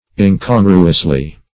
incongruously - definition of incongruously - synonyms, pronunciation, spelling from Free Dictionary
-- In*con"gru*ous*ly, adv.